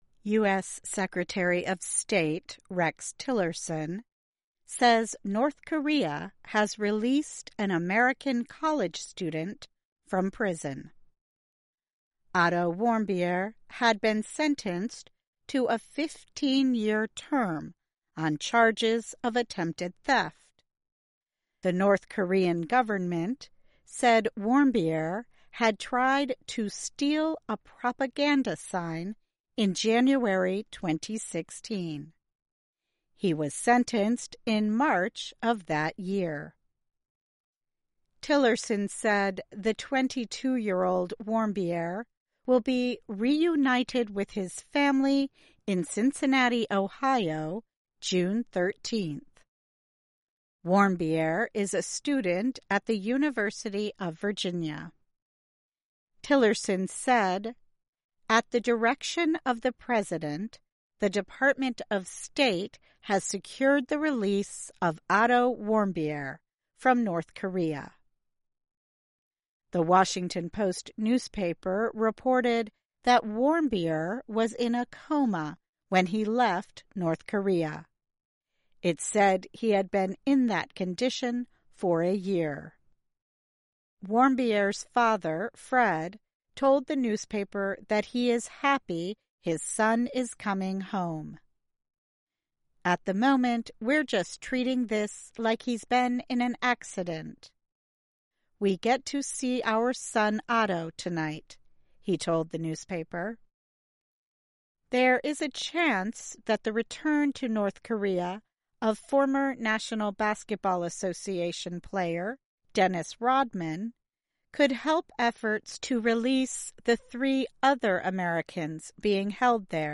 慢速英语:American Prisoner Released by North Korea